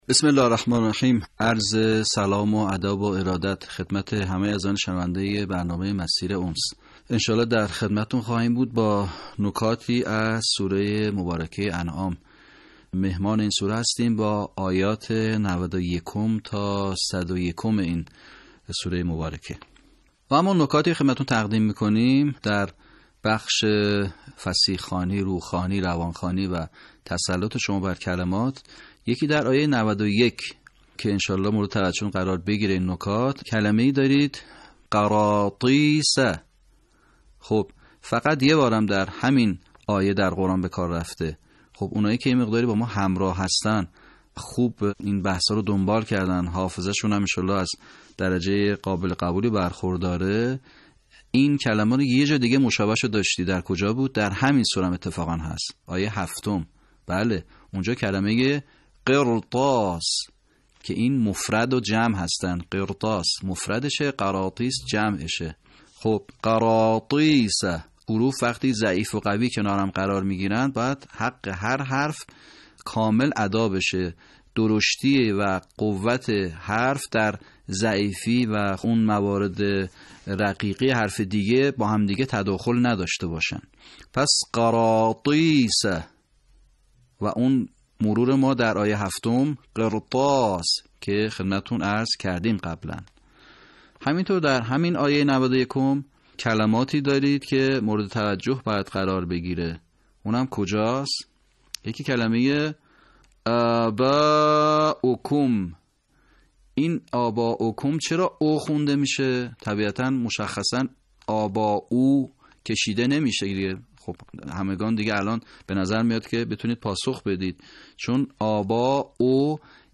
صوت | آموزش صحیح‌خوانی آیات ۹۱ تا ۱۰۱ سوره انعام
به همین منظور مجموعه آموزشی شنیداری (صوتی) قرآنی را گردآوری و برای علاقه‌مندان بازنشر می‌کند.